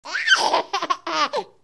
babylaugh2.ogg